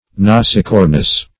Search Result for " nasicornous" : The Collaborative International Dictionary of English v.0.48: Nasicornous \Nas`i*cor"nous\ (n[a^]z`[i^]*k[^o]r"n[u^]s), a. [L. nasus nose + cornu horn: cf. F. nasicorne.]